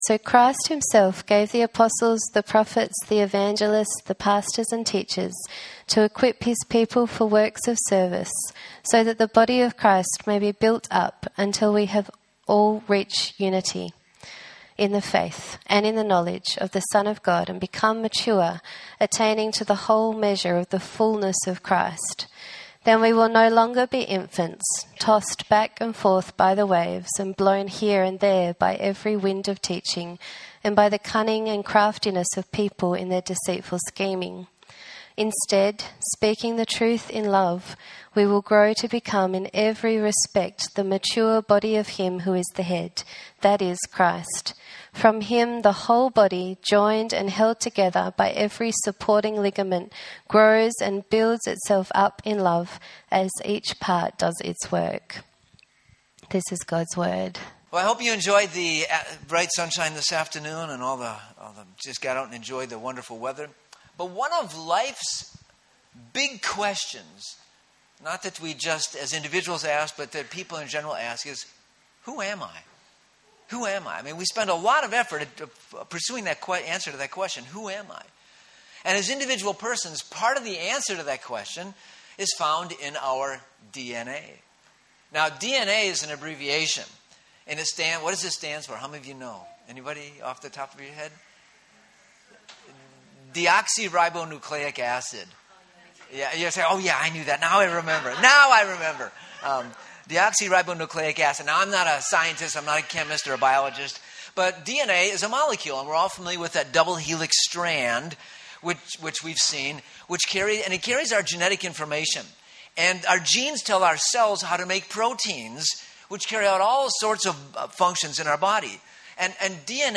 Sermons | Living Water Anglican Church